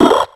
Cri d'Obalie dans Pokémon X et Y.